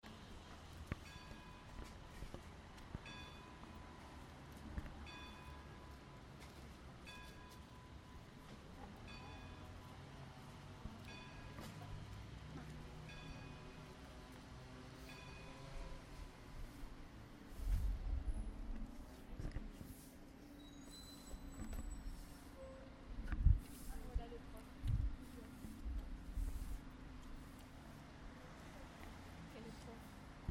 Autour des halles
traffic voitures